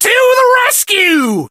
buzz_ulti_vo_01.ogg